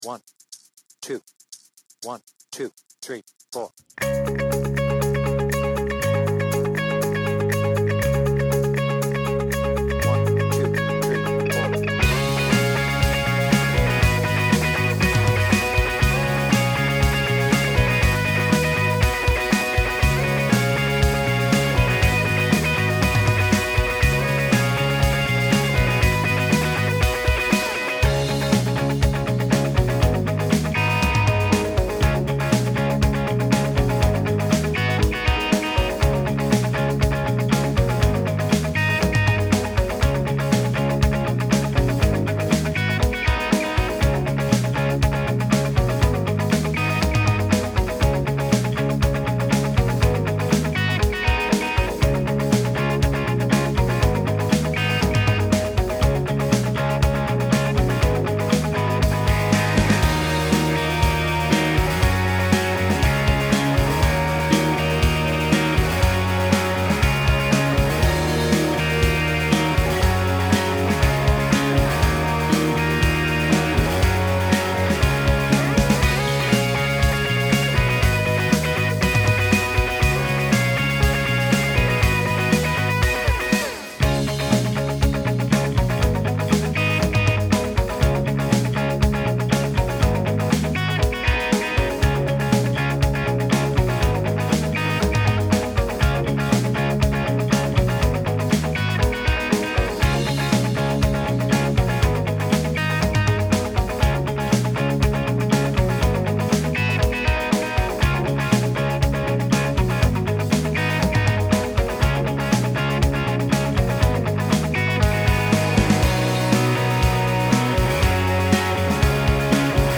BPM : 120
Tuning : Eb
Without vocals